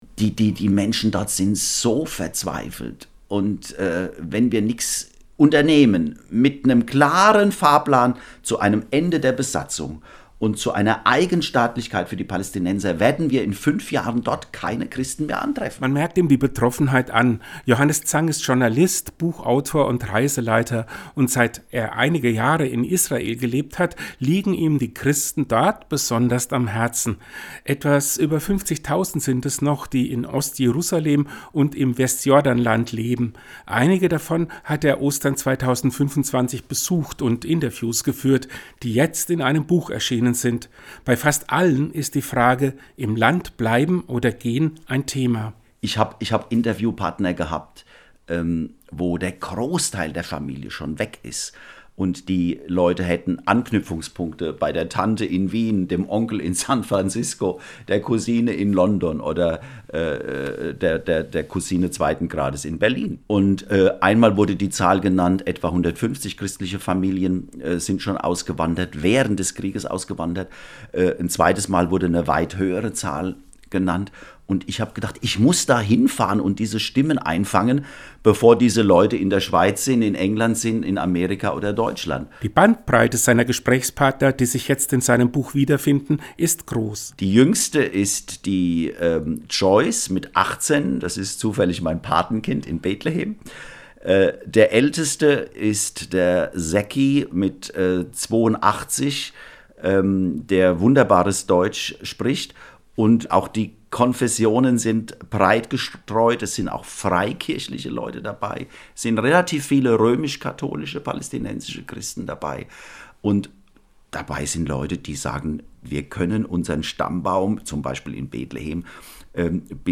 Den Radiopodcast finden sie unten als Download!